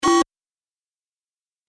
دانلود آهنگ خطا 2 از افکت صوتی اشیاء
دانلود صدای خطا 2 از ساعد نیوز با لینک مستقیم و کیفیت بالا
جلوه های صوتی